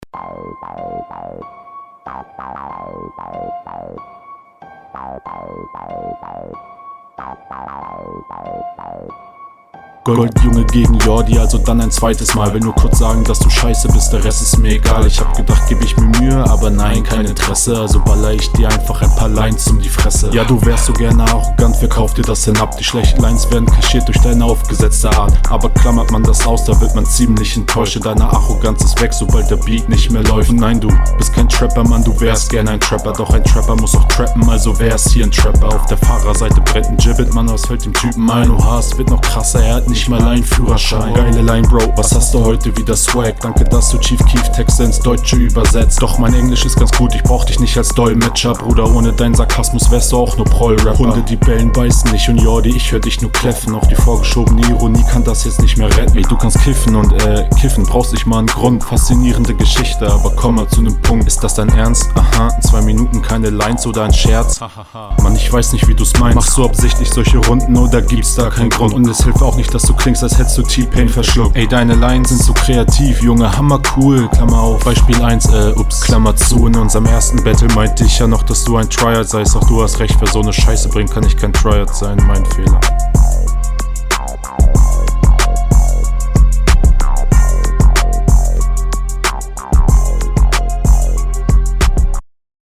Versuch doch mal etwas Varianz in deinen Stimmeneinsatz zu bringen, sonst wird das auf dauer …